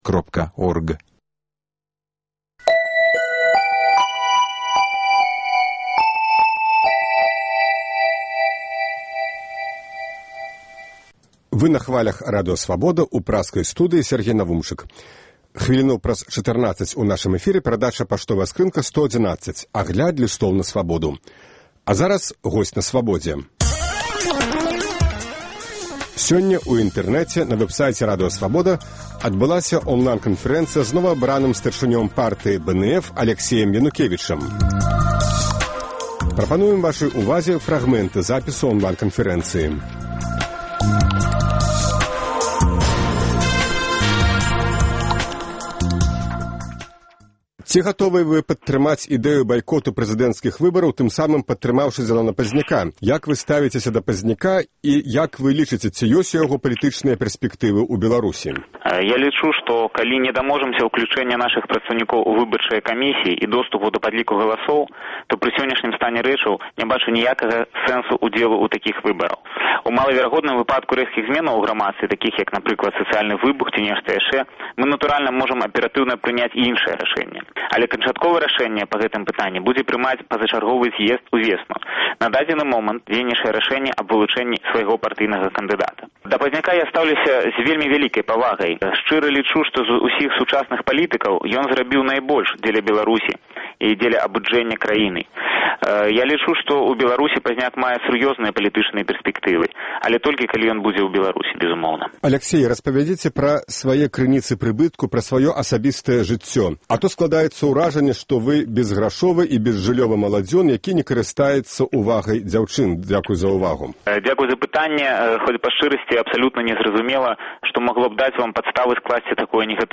Онлайн-канфэрэнцыя